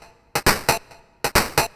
DS 135-BPM C4.wav